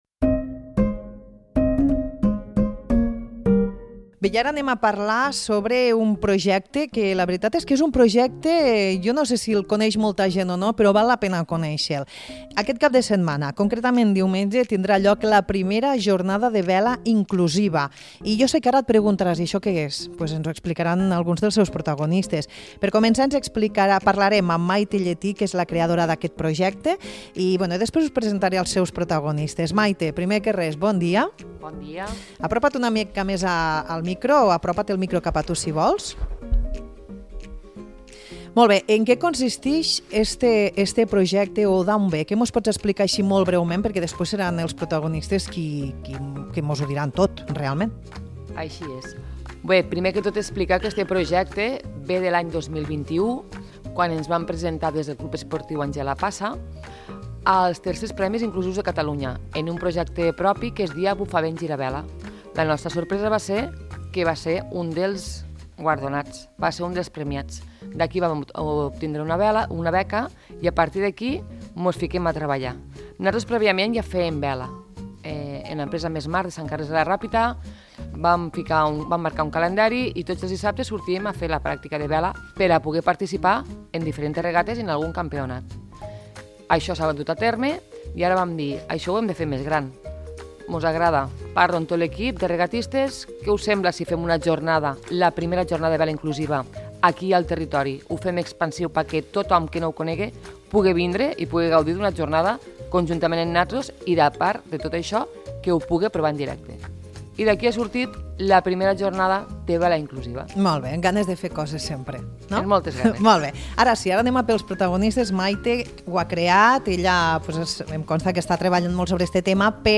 Avui al ‘De bon matí’ ens han visitat quatre dels disset regatistes que formen part de l’equip de vela.